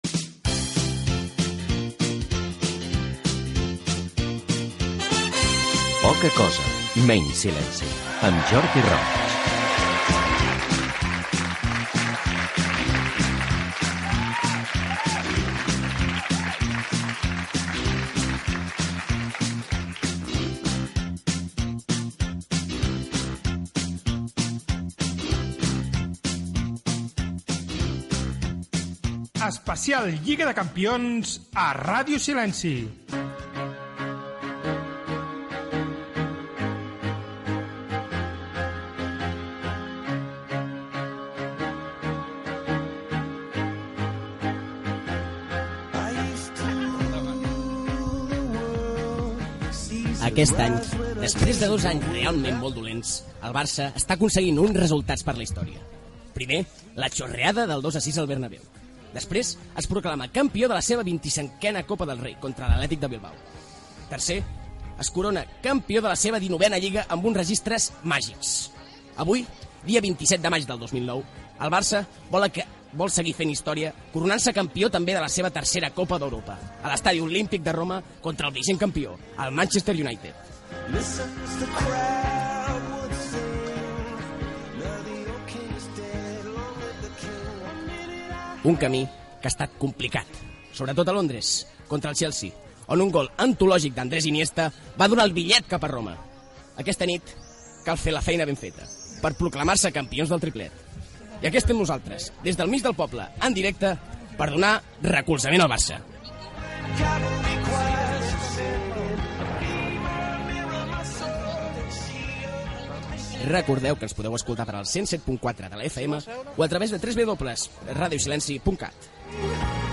Careta, presentació de l'especial Lliga de Campions en el dia que el Futbol Club Barcelona juga la final de la Copa d'Europa de futbol masculí a la ciutat de Milà, Entrevista al jugador de futbol Gerard López